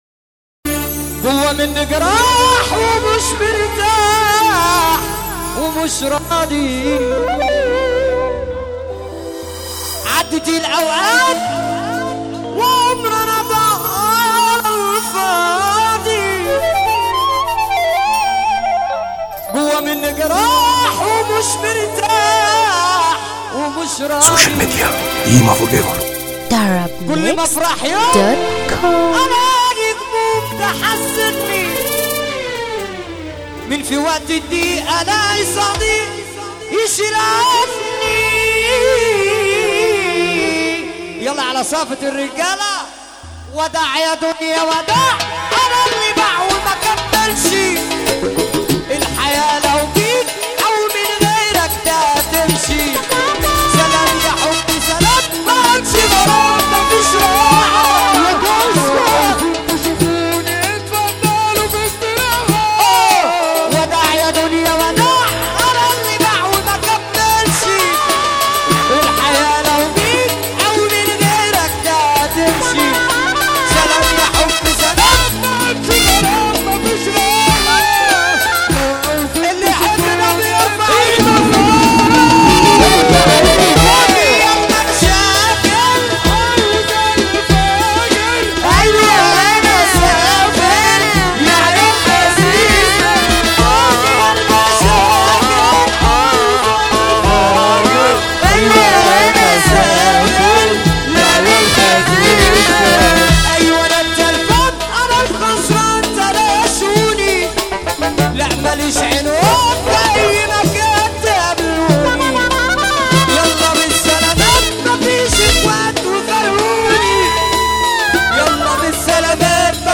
موال
حزينة موت